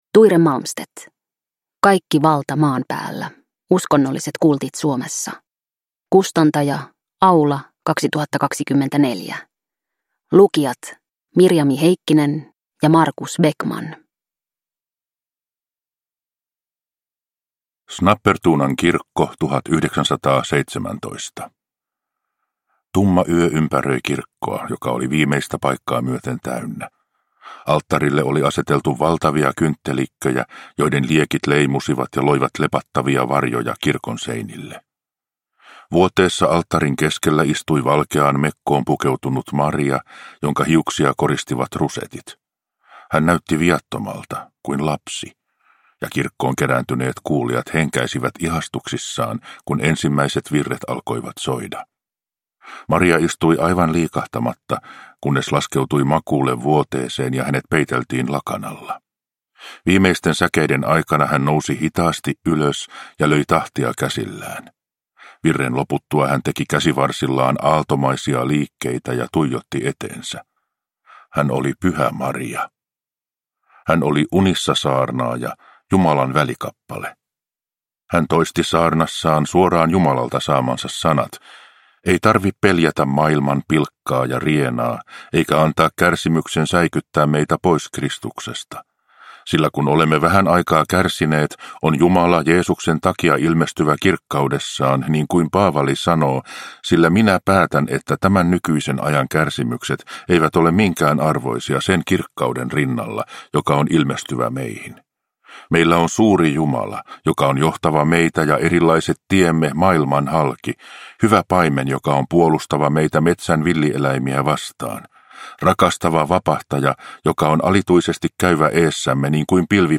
Uppläsare:
Ljudbok